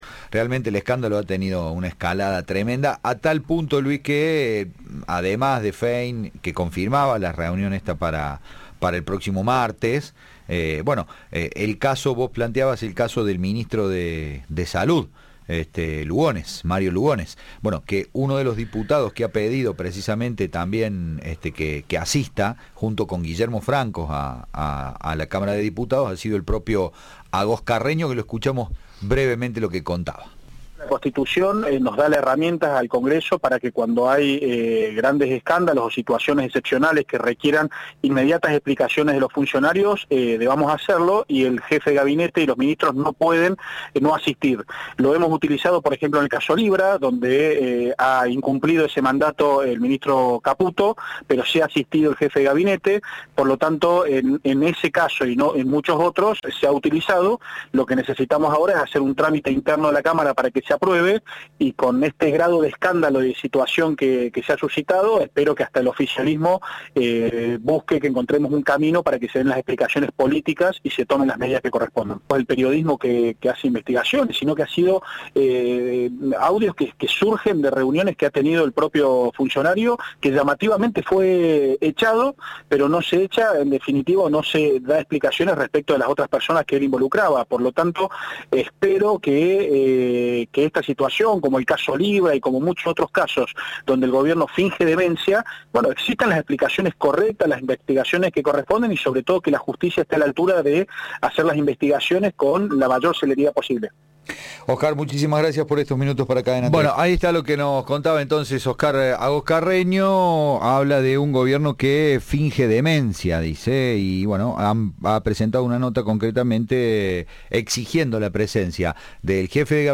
“Con este grado de escándalo, espero que desde el propio Gobierno brinden explicaciones políticas y se tomen medidas", sostuvo a Cadena 3 el diputado nacional Oscar Agost Carreño.